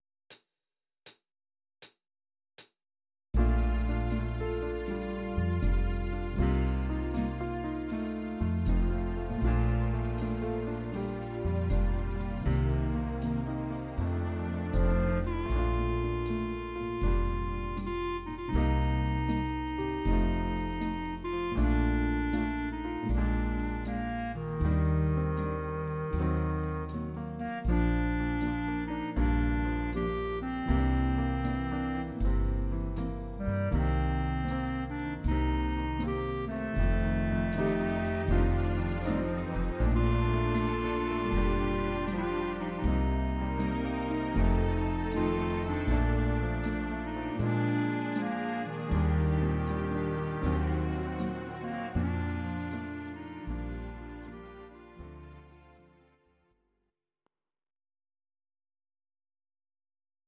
Audio Recordings based on Midi-files
Oldies, Jazz/Big Band, Instrumental, 1960s